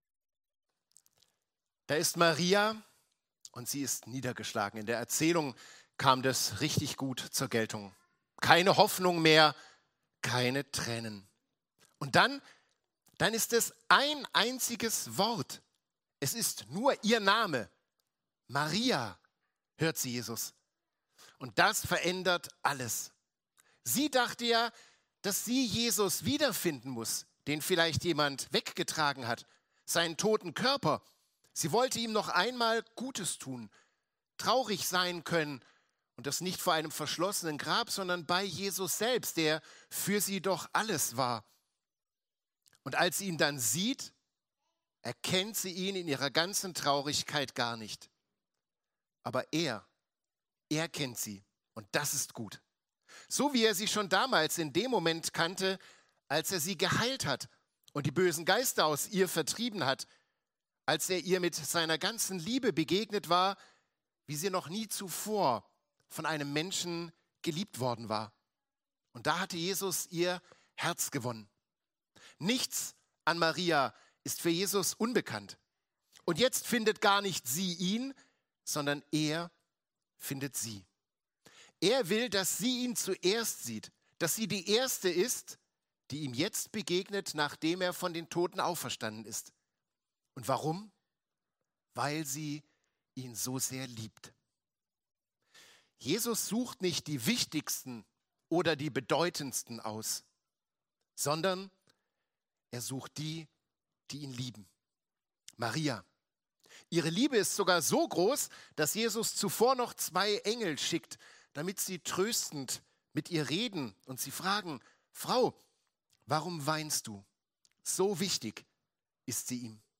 Familiengottesdienst am Ostersonntag